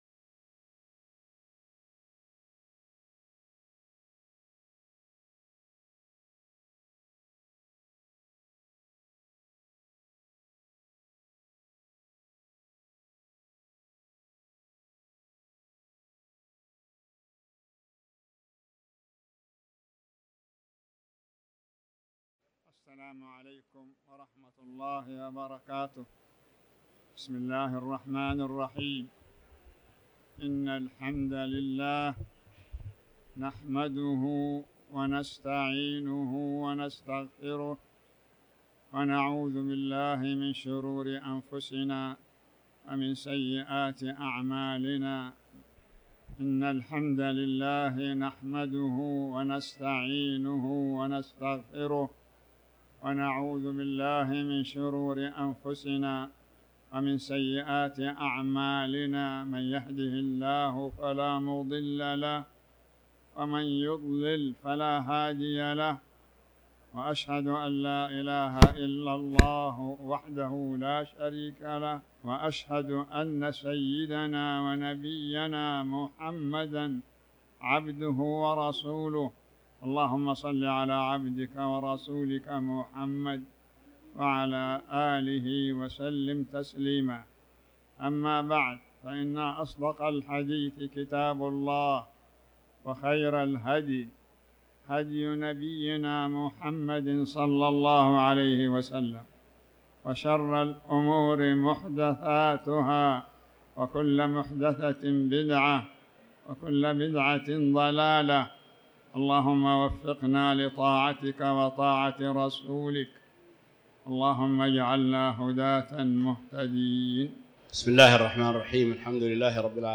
تاريخ النشر ١٦ شوال ١٤٤٠ هـ المكان: المسجد الحرام الشيخ